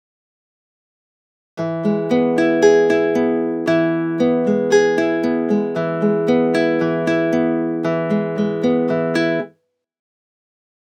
Saturation
The guitar track was processed with the equalizer before being processed by the saturation.
07 guitar (after Little EQ saturation input ga.wav